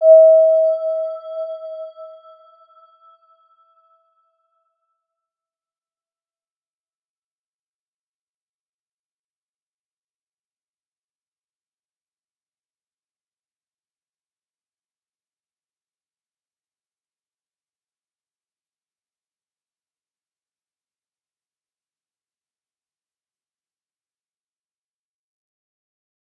Round-Bell-E5-p.wav